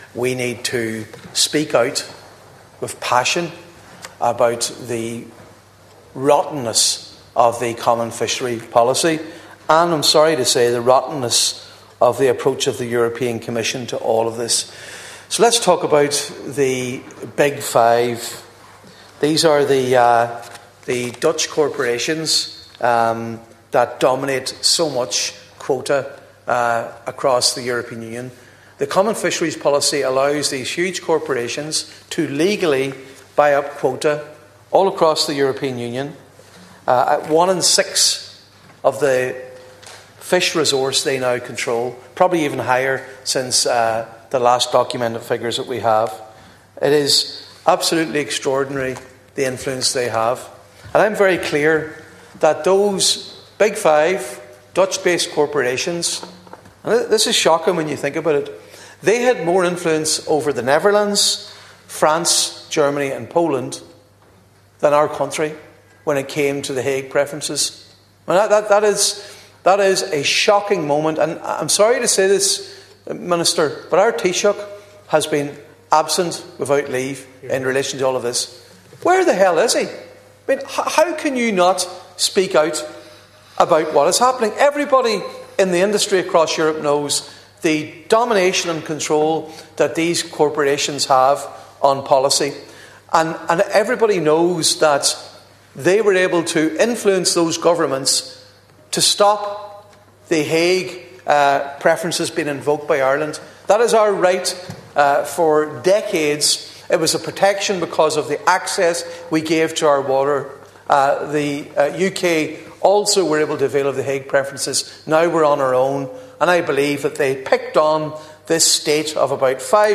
Speaking during statements on fishing quotas, Deputy Padraig MacLochlainn again claimed these corporations were central to the decision to set aside the Hague Preferences in December, effectively removing a mechanism designed to ensure Ireland had a fair deal.
Calling on Taoiseach Michael Martin to do more to represent Ireland’s interests, Deputy MacLochlainn told the Dail that Germany, France, Poland and the Netherlands had not only betrayed Ireland, but also what should be key principles of the Common Fisheries Policy……..